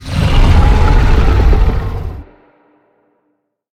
Sfx_creature_shadowleviathan_roar_enter_01.ogg